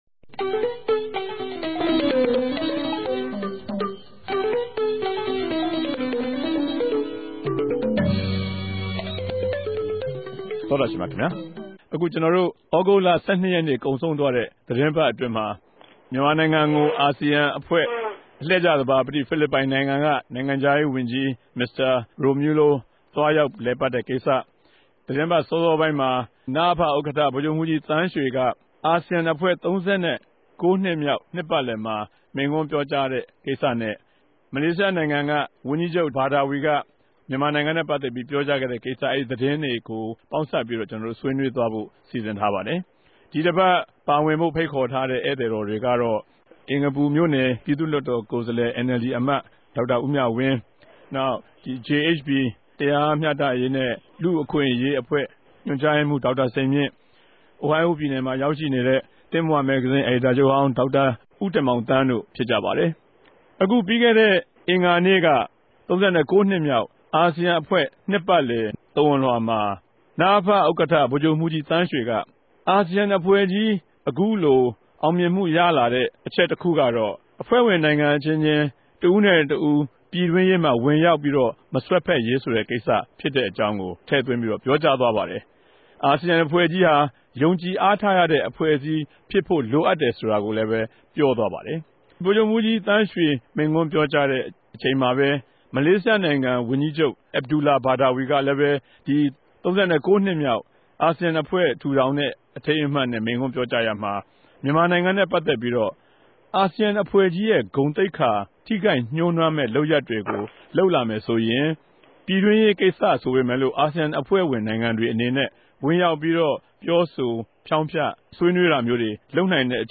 တပတ်အတြင်းသတင်းသုံးသပ်ခဵက် စကားဝိုင်း (၂၀၀၆ ုသဂုတ်လ ၁၂ရက်)